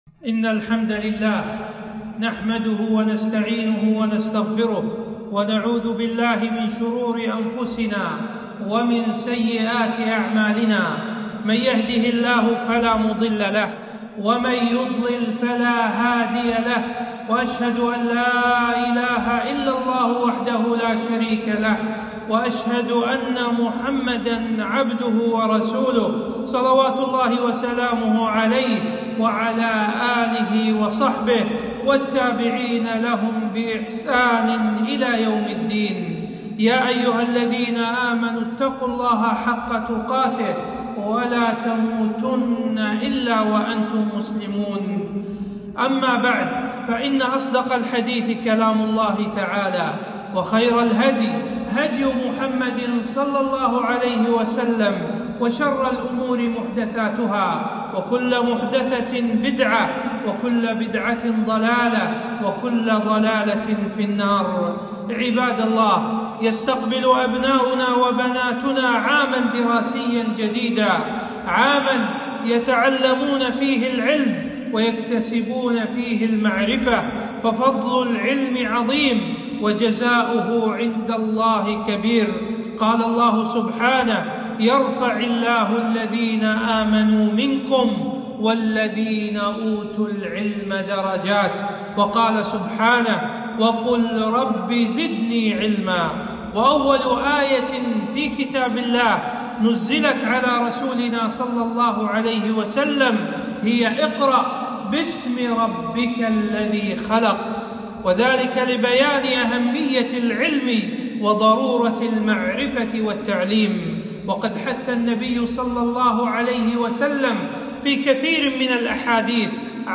خطبة - خطبة استقبال العام الدراسي الجديد